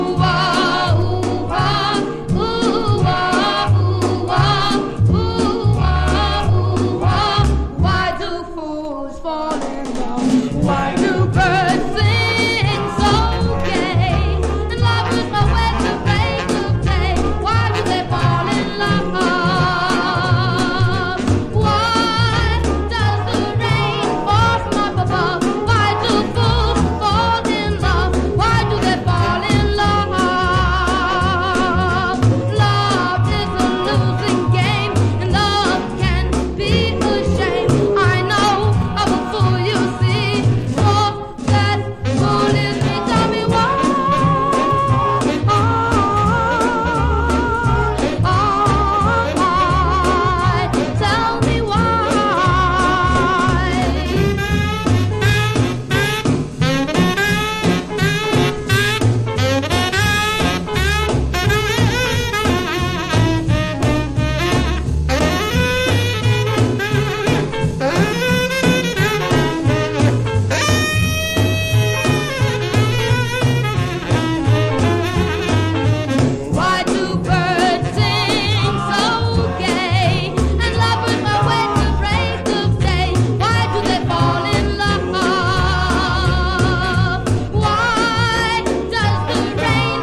当時人気だったキッズR&Bグループの瑞々しいコーラスワークが堪能できます。
VOCAL & POPS